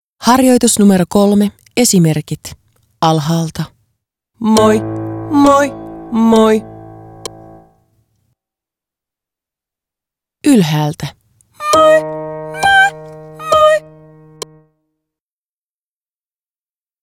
5. Esimerkki 3, moi-tukiharjoitus
05-Esimerkki-3-moi-tukiharjoitus.m4a